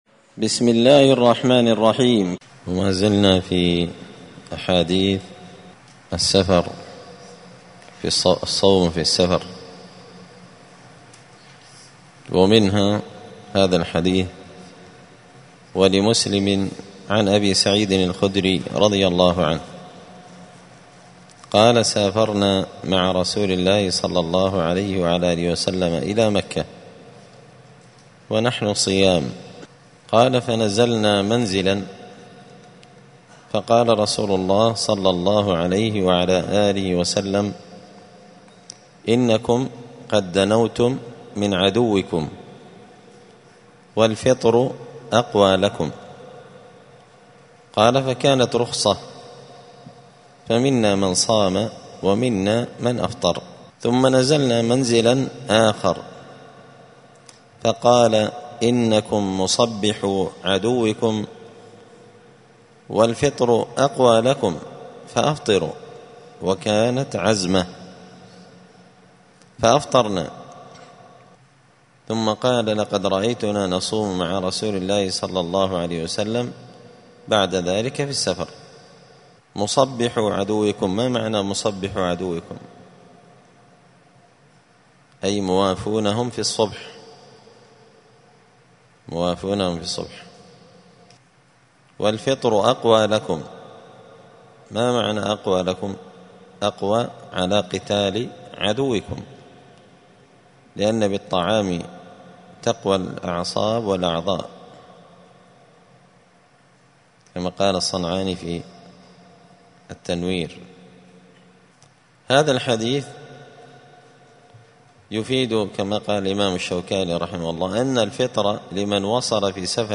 دار الحديث السلفية بمسجد الفرقان بقشن المهرة اليمن
*الدرس السادس عشر (16) {حكم الصيام في الغزو…}*